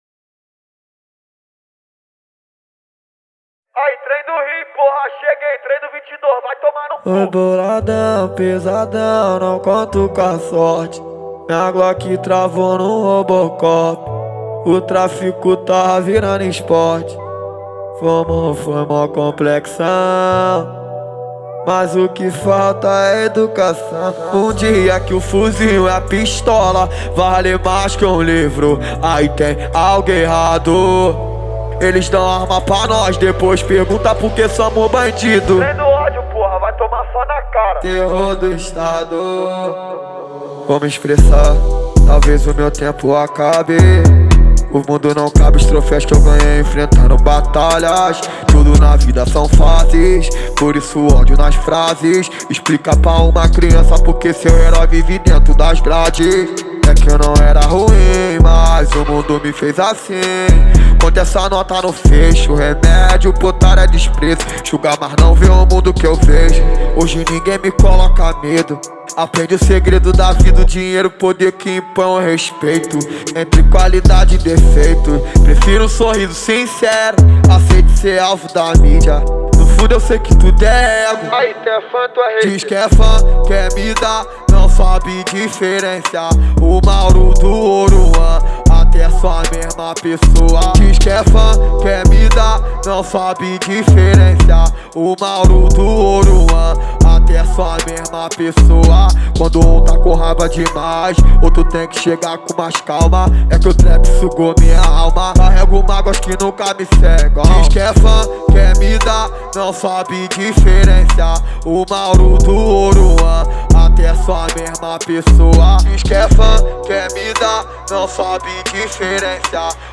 2025-04-20 14:49:52 Gênero: Trap Views